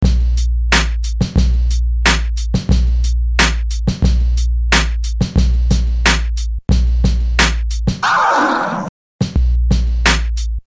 描述：12个满足扬声器的振动器式低音。
标签： 90 bpm Hip Hop Loops Drum Loops 1.79 MB wav Key : Unknown
声道立体声